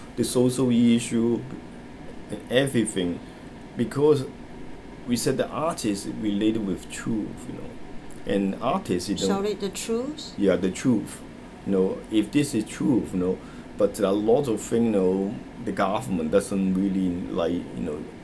S1 = Taiwanese female S2 = Hong Kong male Context: S2 has just said that the purpose of art is not just to allow people to appreciate beauty, but also to raise awareness.
The main problem is that the sound at the start of related is pronounced as [w] .